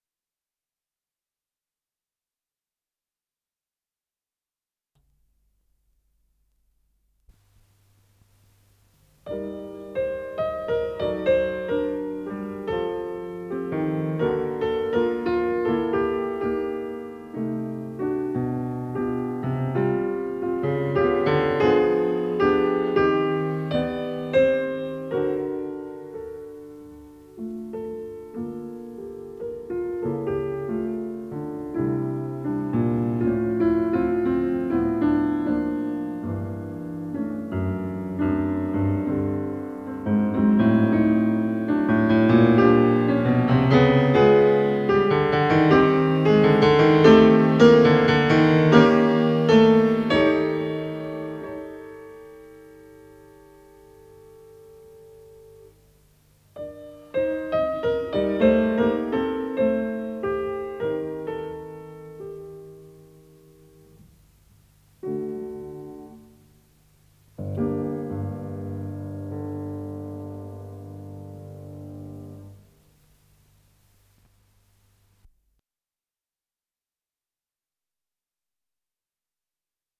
с профессиональной магнитной ленты
фортепиано
ВариантДубль моно